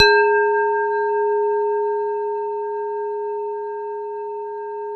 WHINE  G#2-L.wav